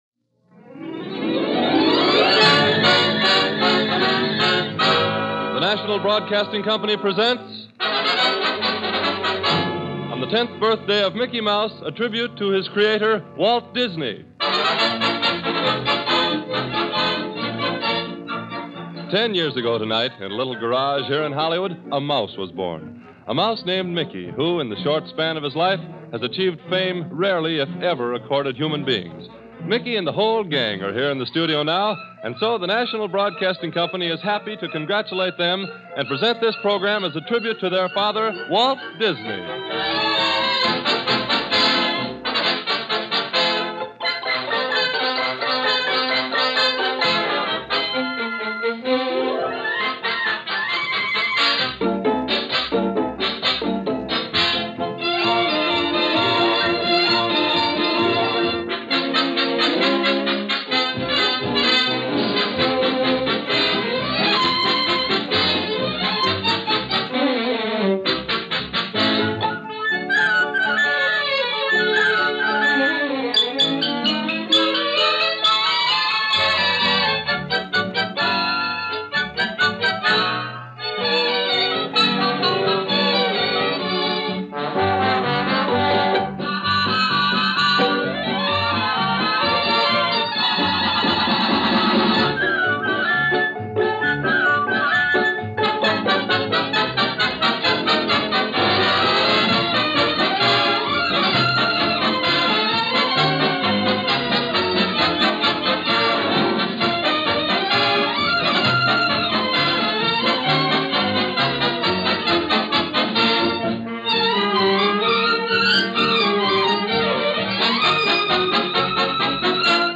This special program, produced by NBC Radio on September 27, 1938 is a tribute to Mickey Mouse as well as Walt Disney and features many of the Disney characters popular at the time.